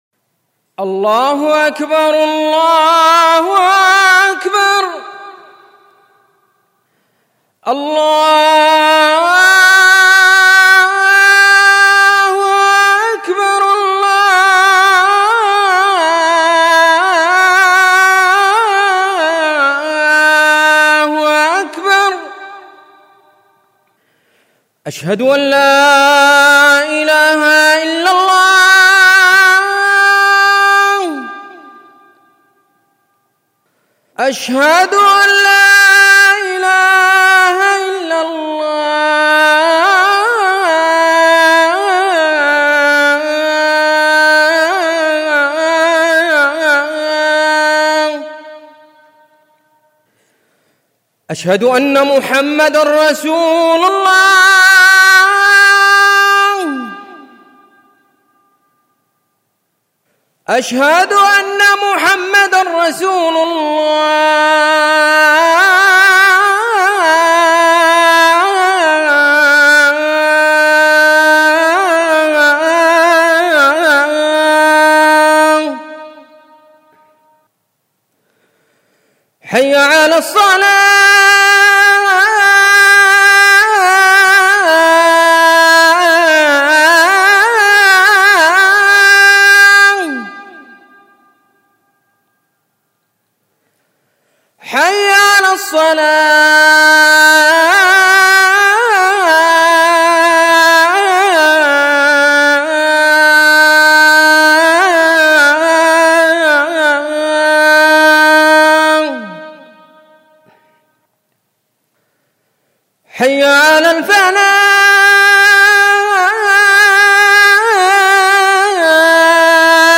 أناشيد ونغمات
عنوان المادة أذان